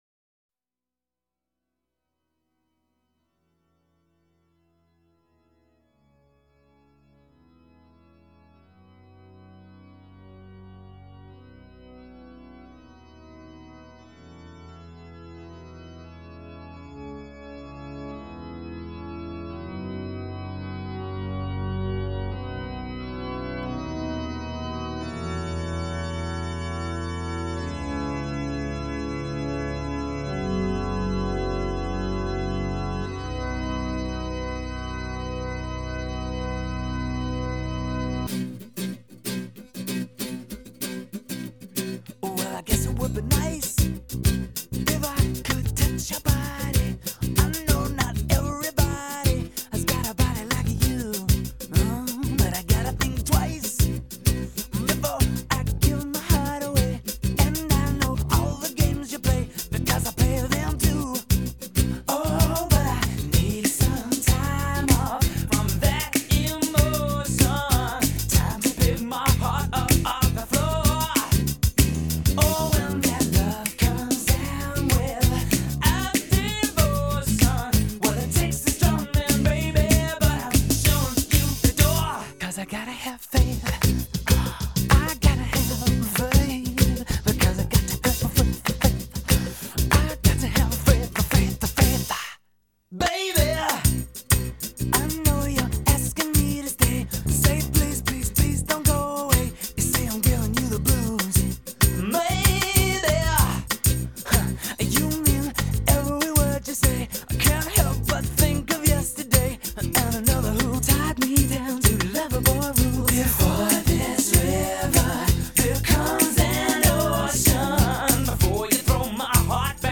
catholic organ intro